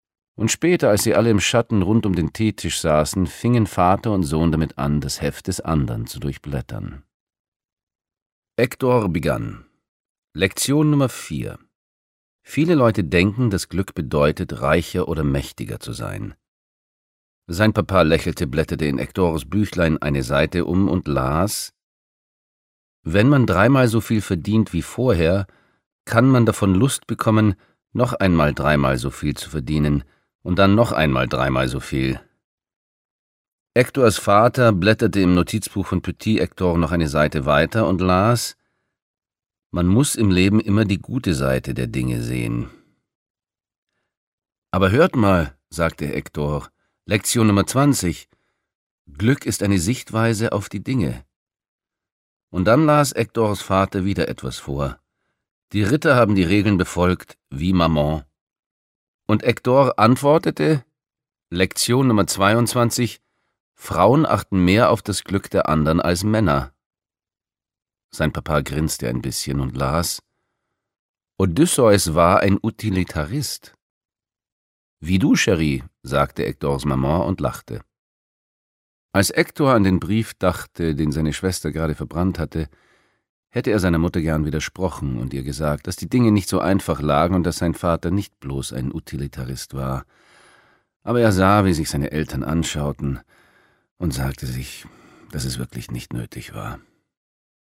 Hector & Hector und die Geheimnisse des Lebens 4 CDs François Lelord (Autor) AUgust Zirner (Komponist) AUgust Zirner (Sprecher) Audio-CD 2013 | 1.